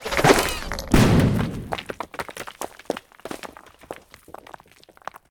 grenade.ogg